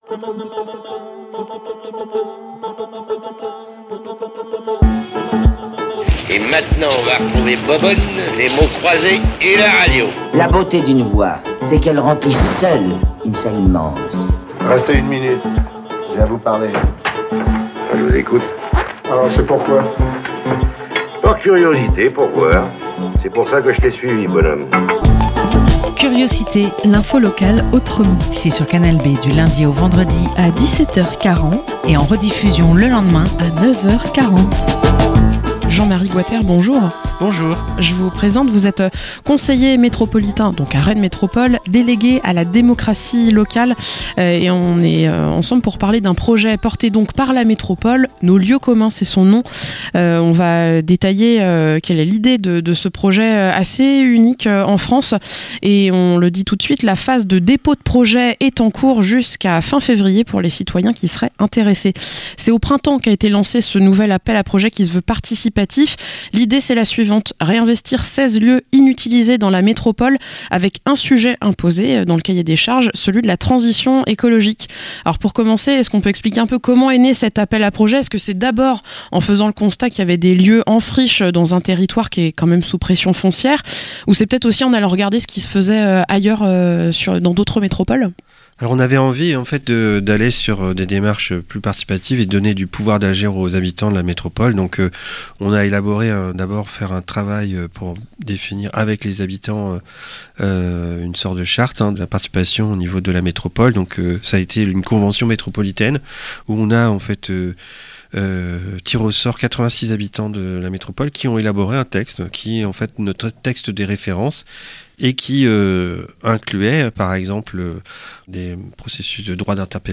- Interview avec Jean-Marie Goater , conseiller métropolitain délégué à la démocratie locale, pour présenter le projet "Nos lieux communs".